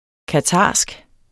qatarsk adjektiv Bøjning -, -e Udtale [ kaˈtɑːsg ] Betydninger fra det mellemøstlige land Qatar; vedr.